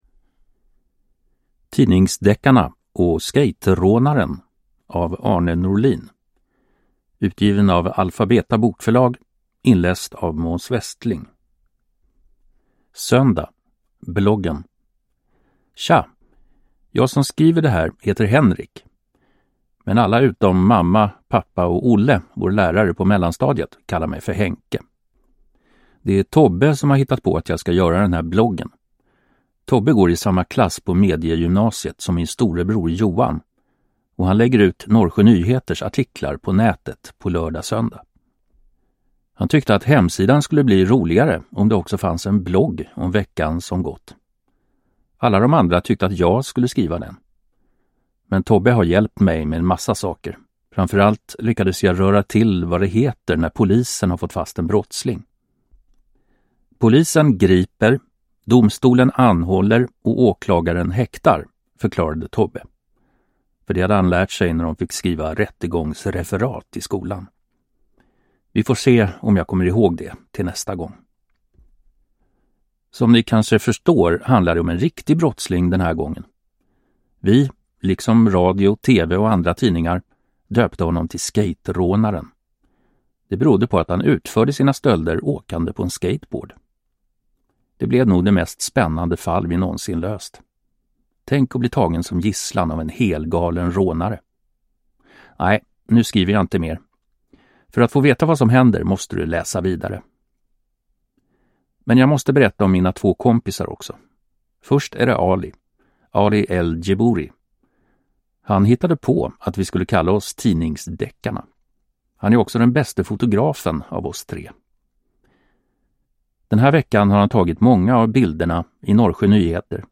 Tidningsdeckarna och skejtrånaren – Ljudbok